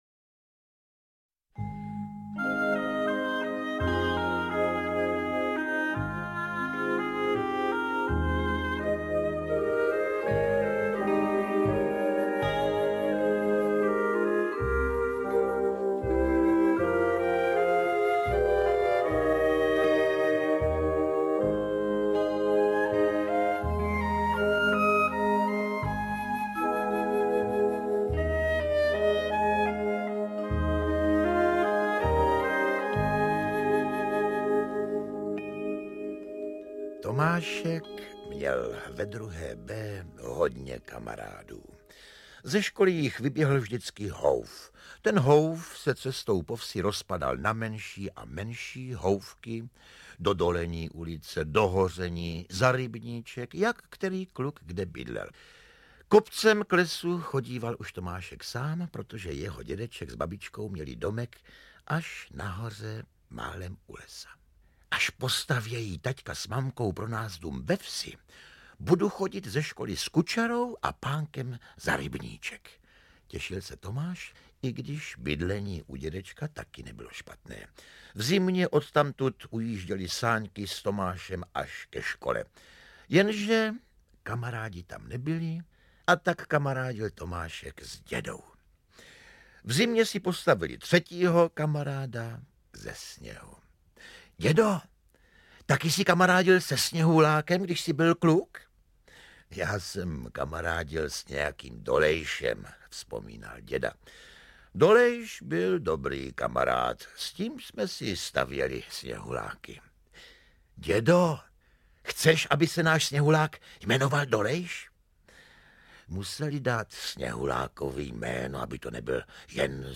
Interpreti:  Eduard Cupák, Libuše Havelková, Jaroslav Kepka, Josef Somr, Ilona Svobodová
V první, v poetické pohádce Marie Kubátové Kam odcházejí sněhuláci zjara malým posluchačům již od čtyř let vypráví Josef Somr o tom, co se stane s vodou ze sněhuláků malého Tomáše na jaře.
Druhá, dramatizovaná pohádka O kozlíčkovi Kryšpínovi a neposlušné...